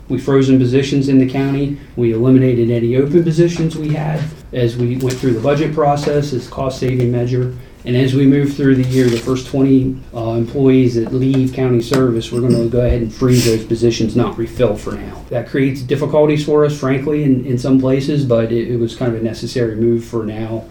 Funding cuts to outside agencies have been part of the plan for FY2025, as well as looking at other county programs that could be pared back.  During the State of the County meeting at Allegany College, County Administrator Jason Bennett said that staffing is also experiencing a budget freeze, with positions going unfilled…